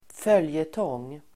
följetong substantiv, serial Uttal: [²f'öl:jetång:] Böjningar: följetongen, följetonger Synonymer: serie Definition: berättelse (i en tidning) som återkommer i flera avsnitt (a story (in a paper) published in episodes)